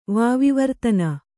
♪ vāvi vartana